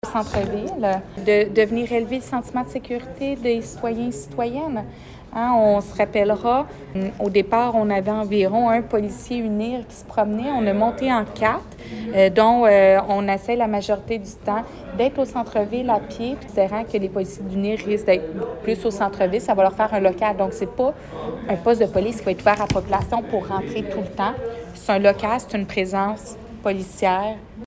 Nouvelles
La mairesse, Julie Bourdon, a annoncé ce projet lors de la séance du conseil municipal, lundi soir.